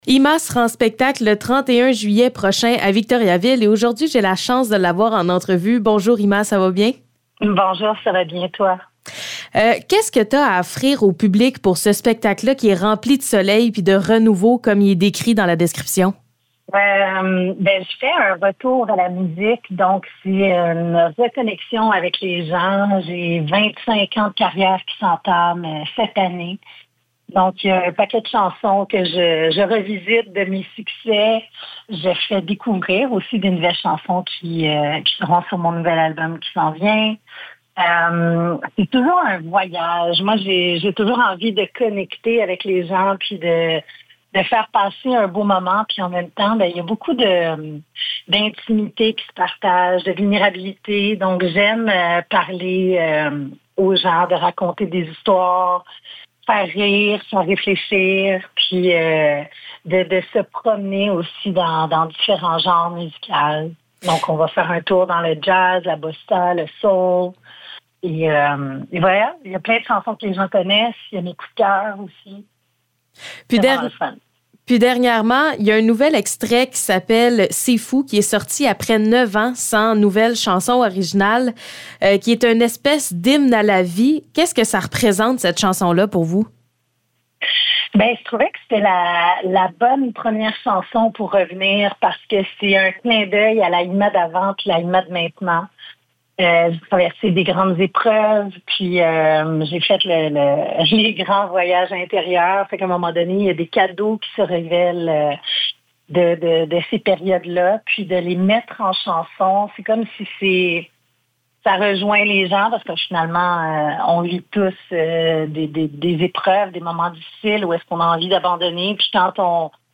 Entrevue avec IMA